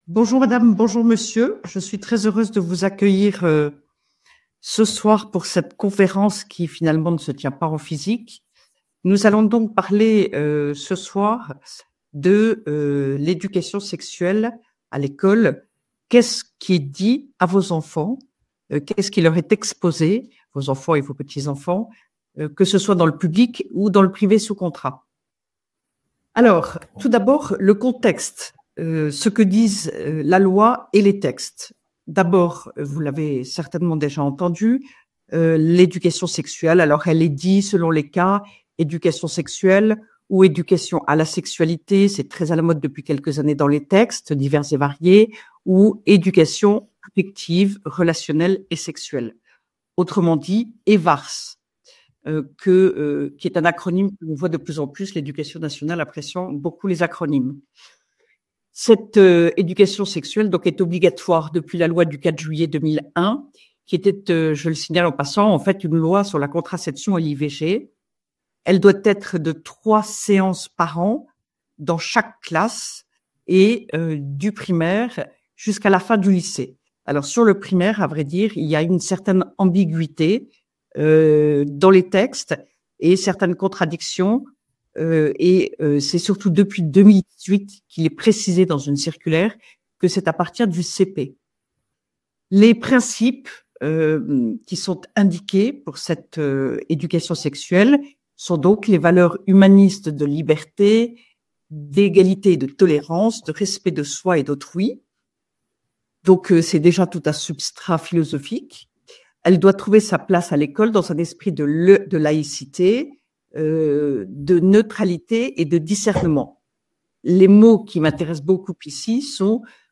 St Etienne. Conf novembre 2024 avec Ludovine de La Rochère